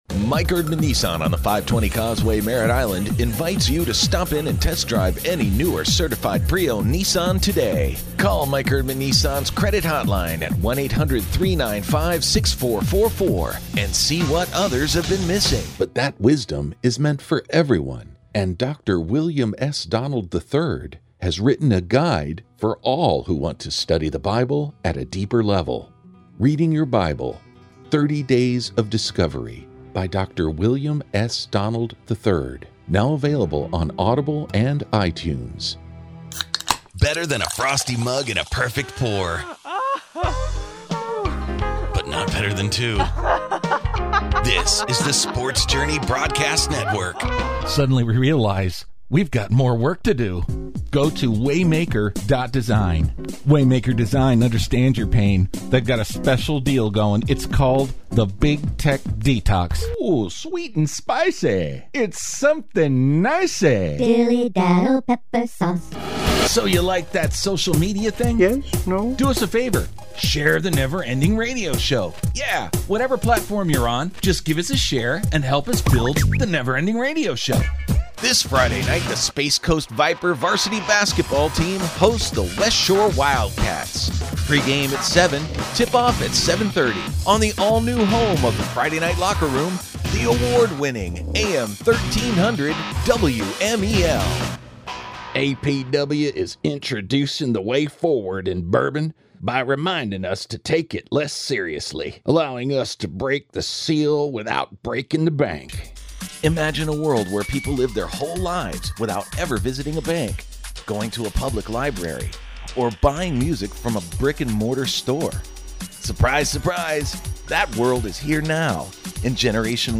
Smooth Voices
Commercial and Imaging